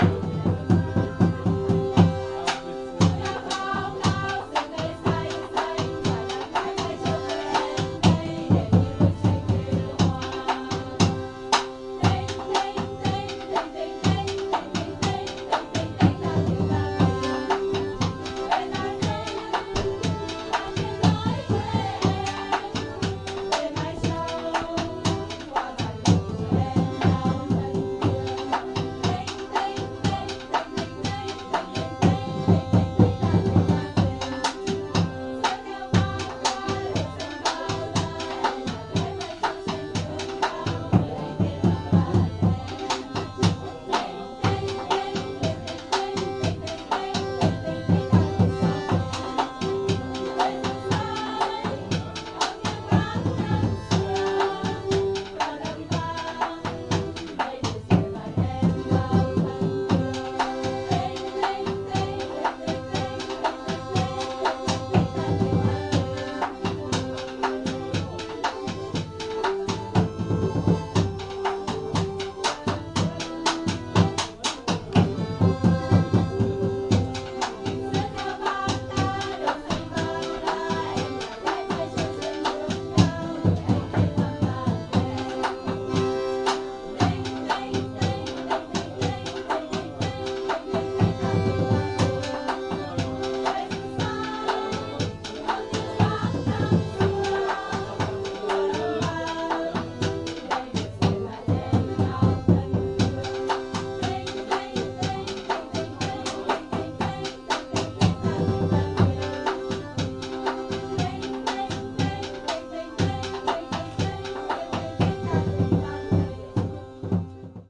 描述：唱歌在一个室内市场上的孩子在葡萄牙。
录制于2018年6月，配有Olympus LS3（内置麦克风，TRESMIC ON）。
Tag: 鼓掌 市场 儿童 唱歌 氛围 室内 歌曲 葡萄牙 现场录音